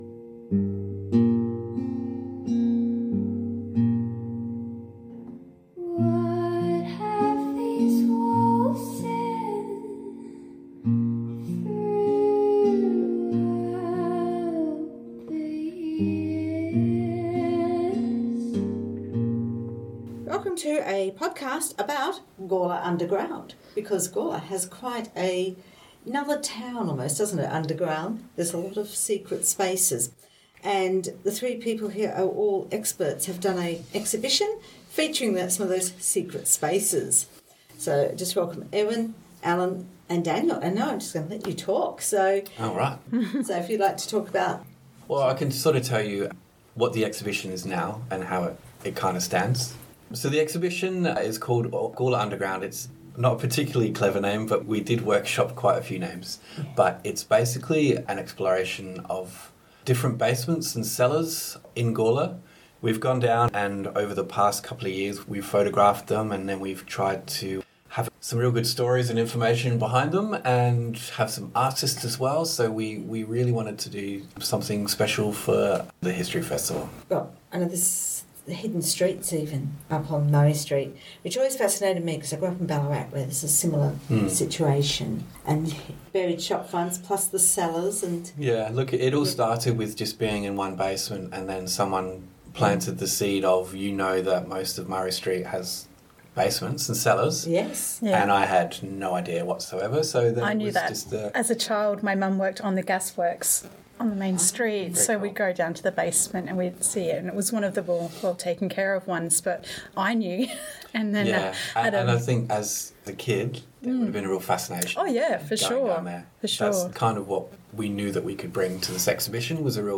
This podcast features music, composed for the…